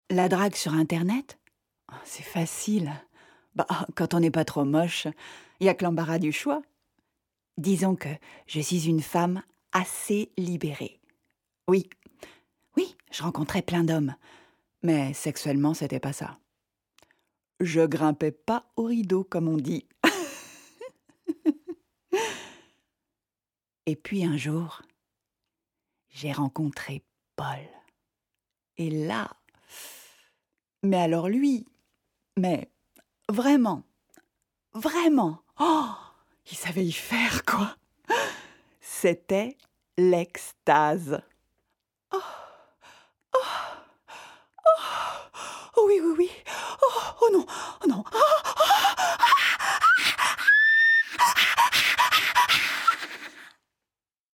Publicité pour une voiture sur internet